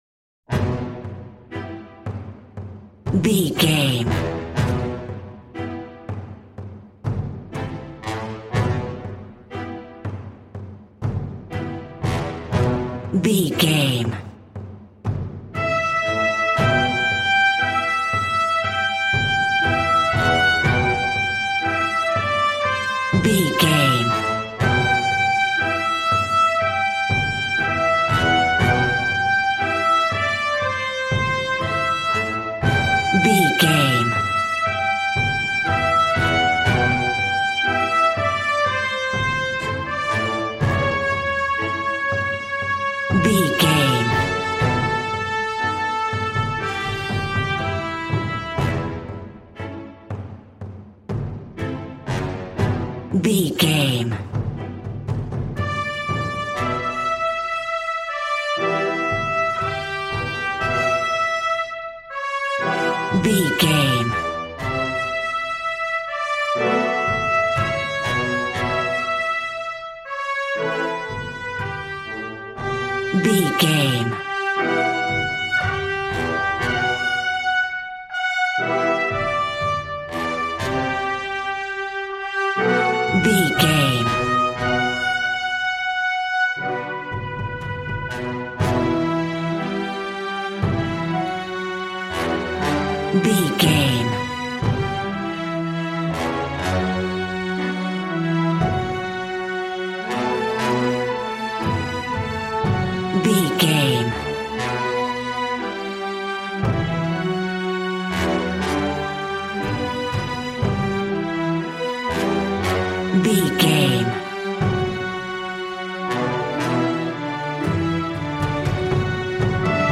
Aeolian/Minor
brass
strings
violin
regal